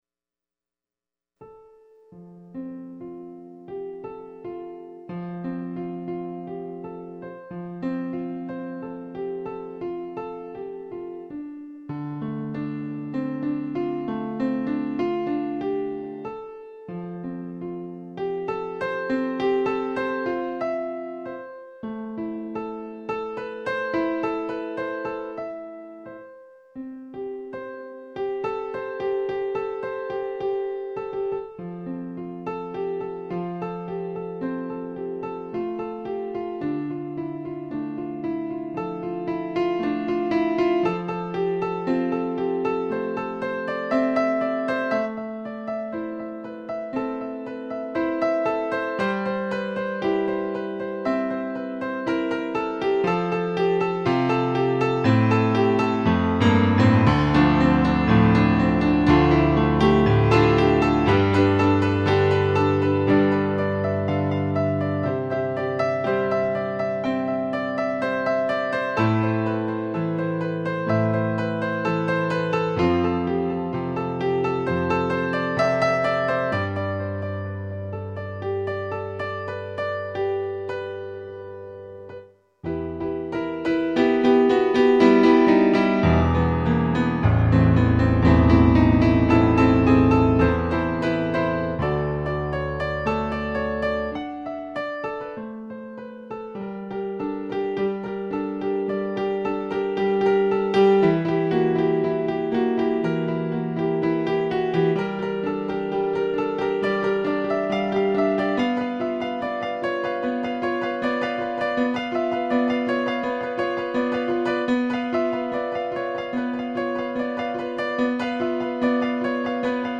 I also play & compose piano music.
These songs are instrumental ... wish they had lyrics but so far none have come to mind.
I think it is delicate and dramatic.